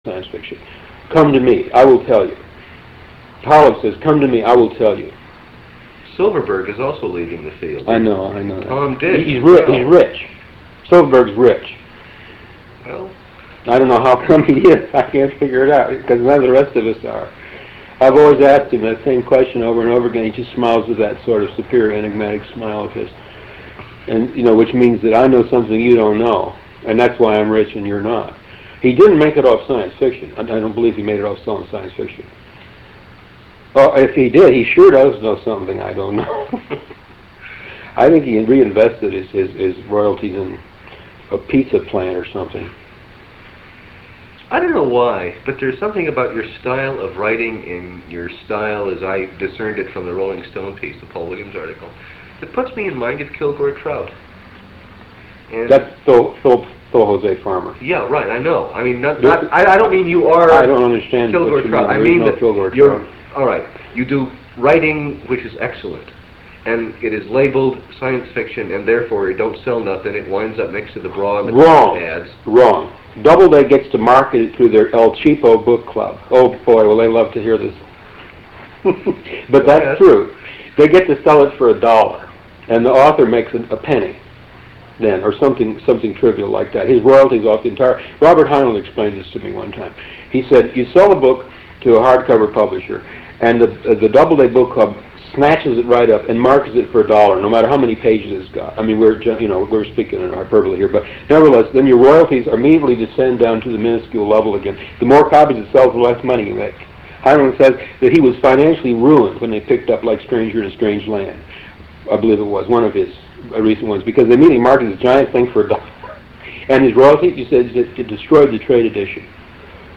Interview with Philip K Dick 2